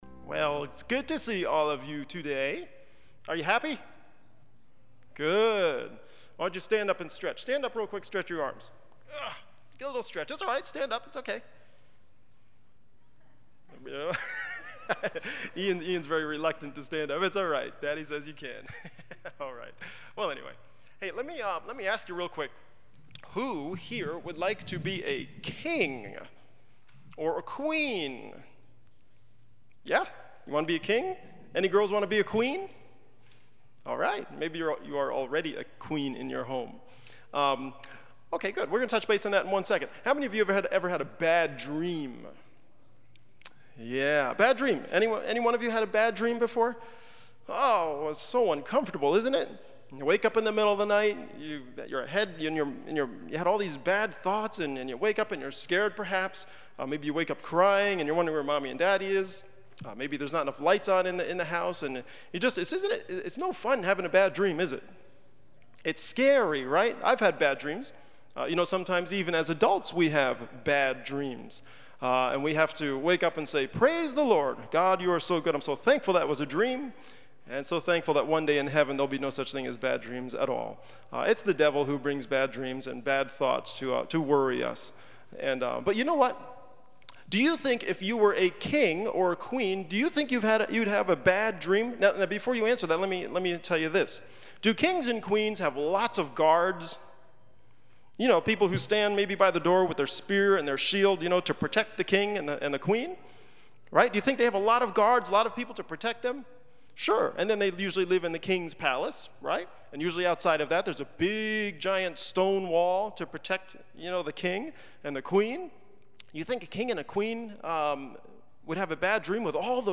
Children Story